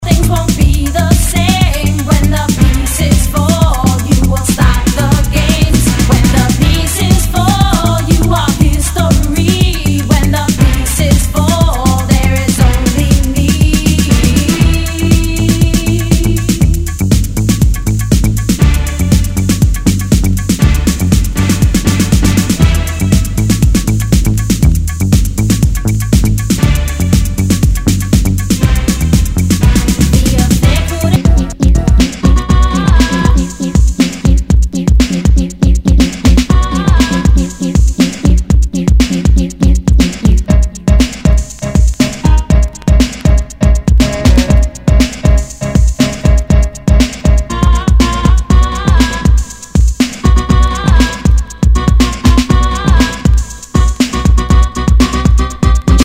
HOUSE/TECHNO/ELECTRO
ヴォーカル・ハウス・クラシック！